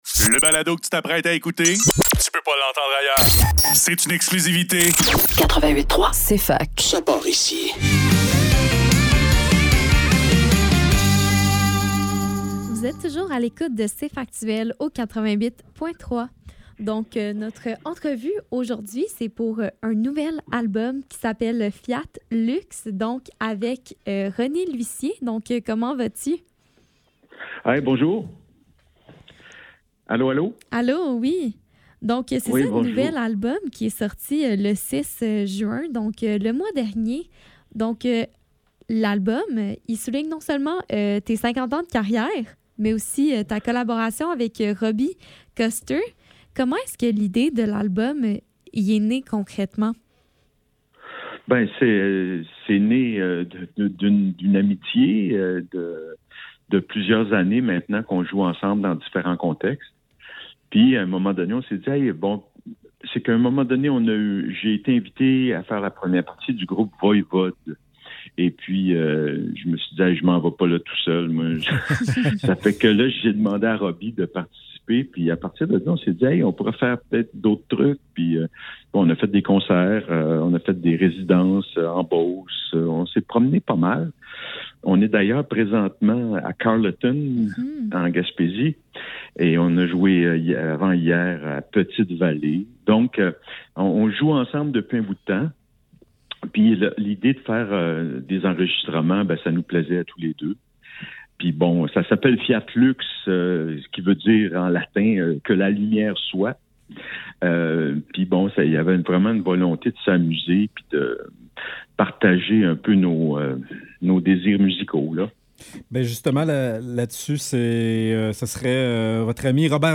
Cfaktuel - Entrevue : René Lussier - 07 Juillet 2025